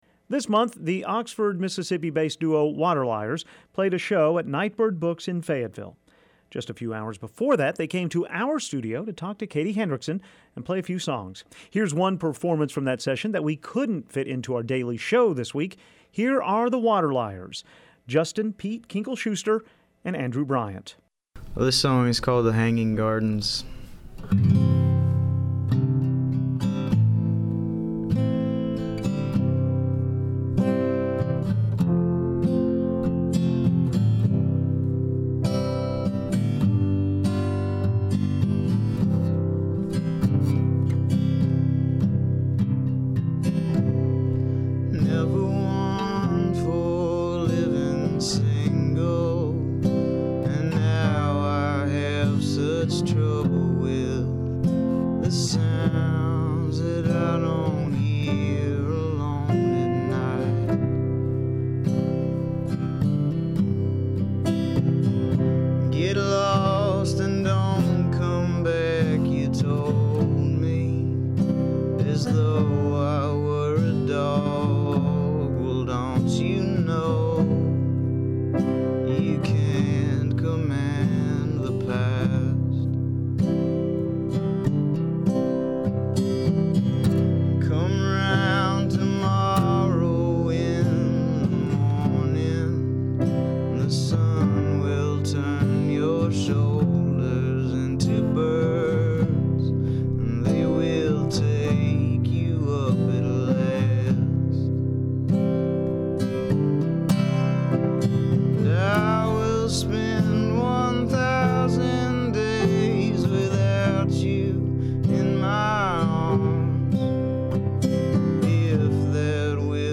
play inside our studio